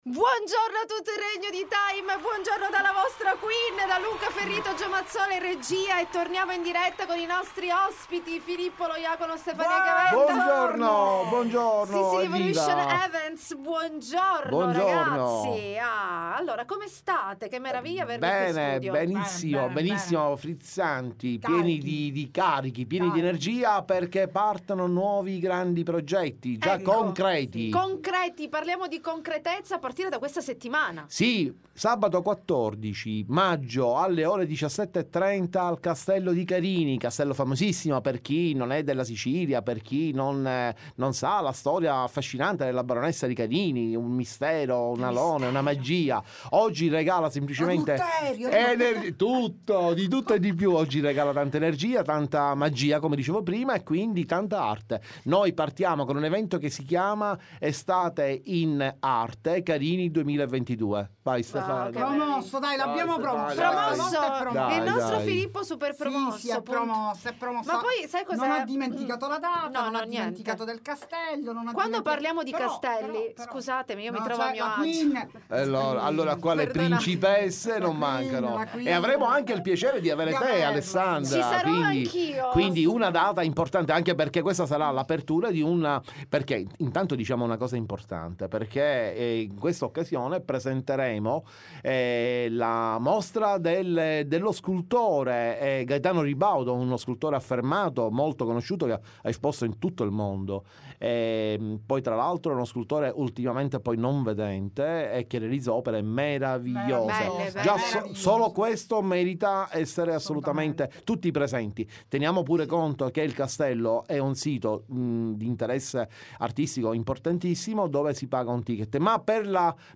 L.T. Intervista Sicily Evolution Events